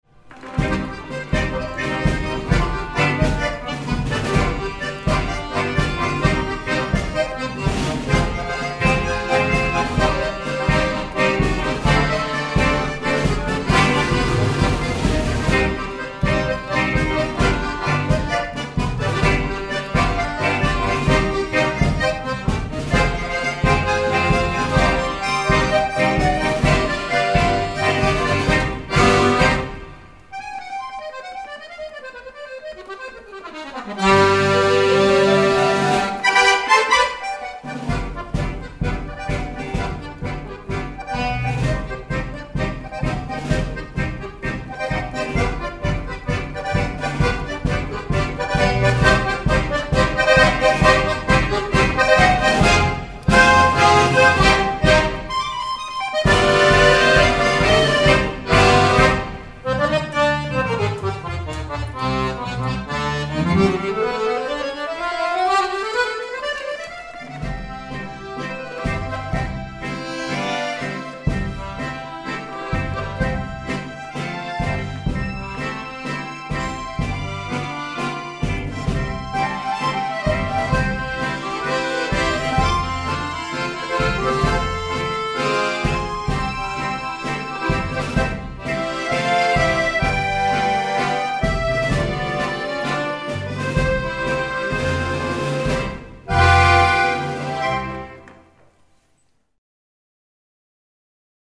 2010 – Akkordeonorchester Neustadt bei Coburg e. V.